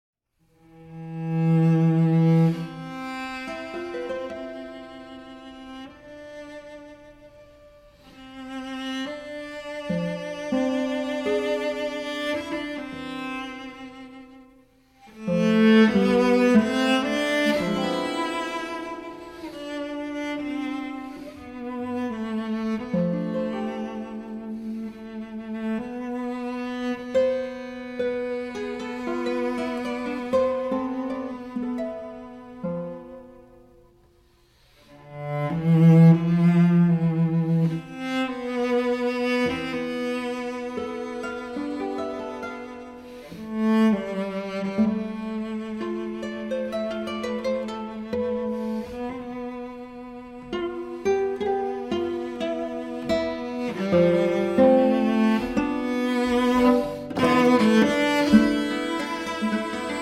Guitar
Flute
Tenor
Cello
Soprano
Double Bass